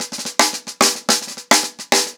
TheQuest-110BPM.25.wav